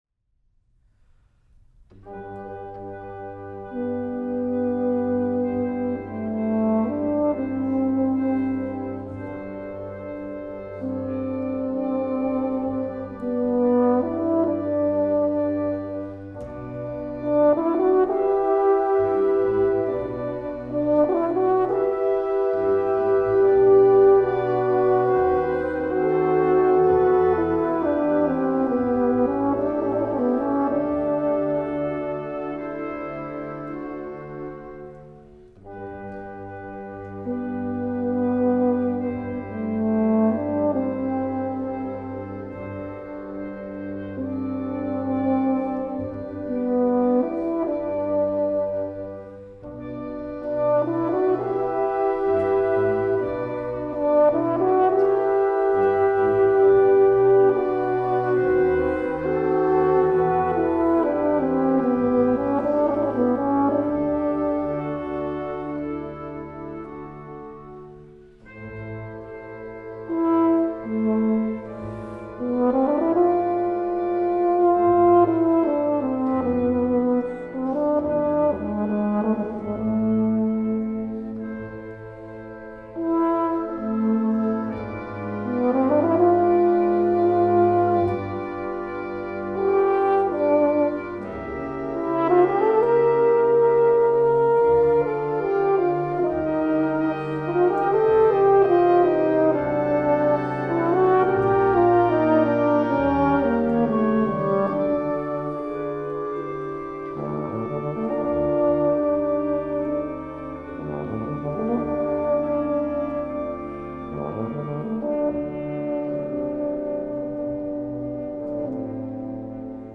Voicing: Euphonium Solo w/ Band